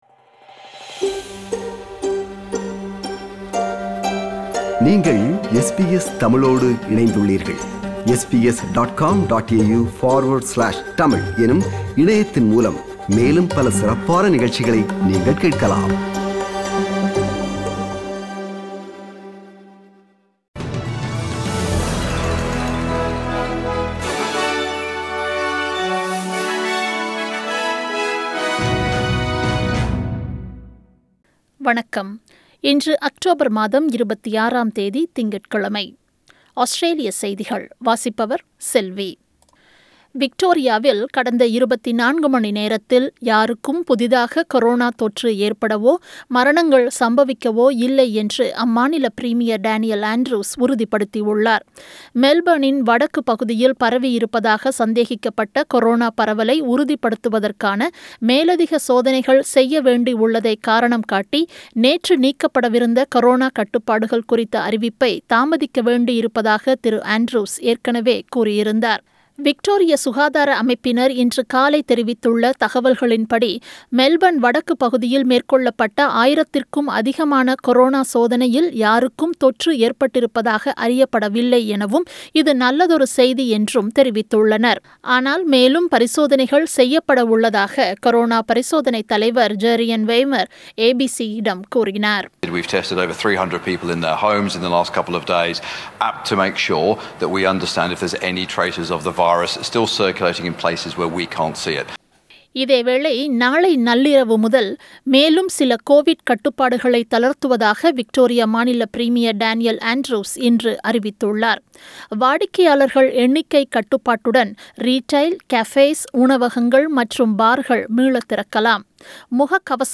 Australian news bulletin for Monday 26 October 2020.